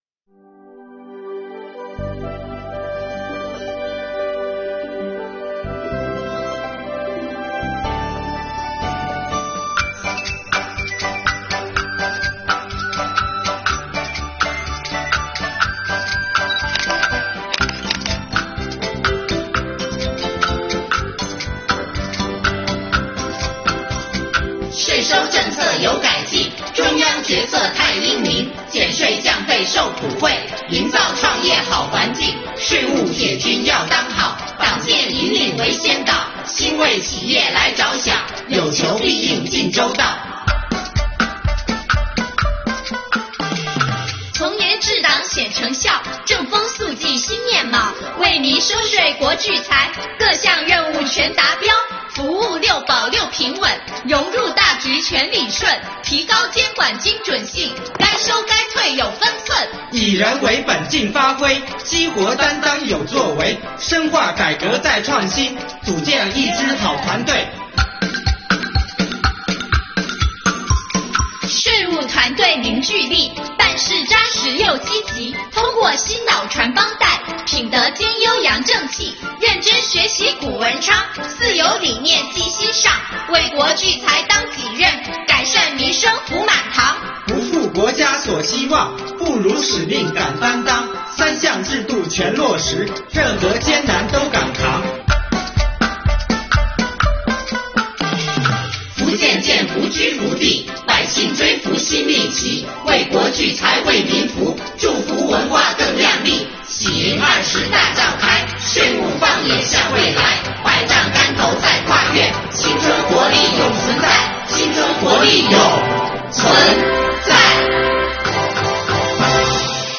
标题: 快板 | 税眼向未来